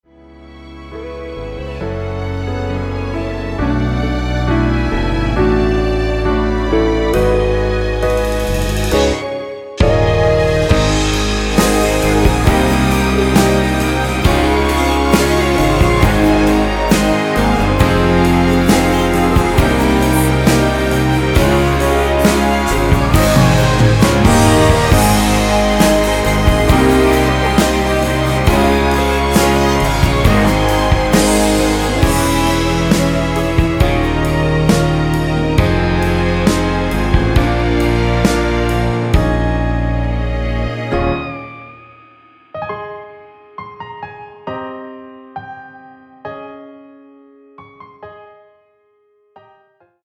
이곡의 코러스는 미리듣기에 나오는 부분밖에 없으니 참고 하시면 되겠습니다.
원키에서(+4)올린 코러스 포함된 MR입니다.(미리듣기 확인)
F#